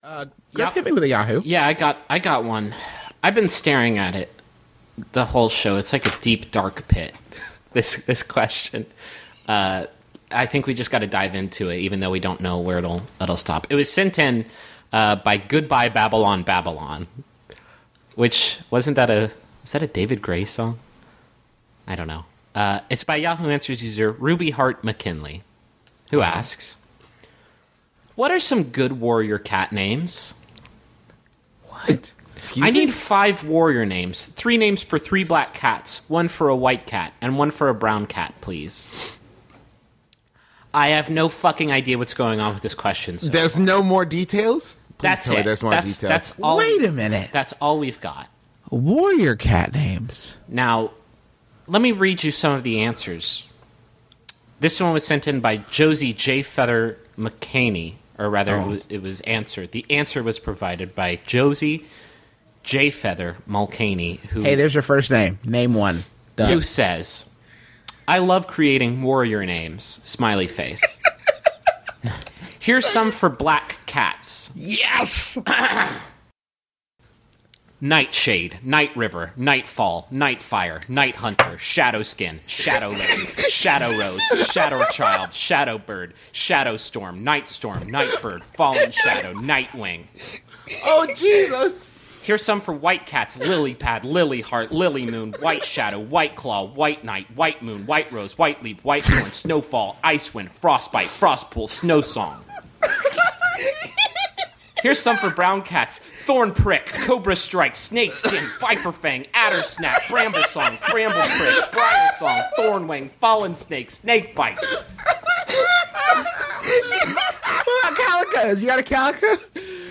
3 grown men with no knowledge of the Warriors series of books discover a list of names for warrior cats on Yahoo answers, and lose it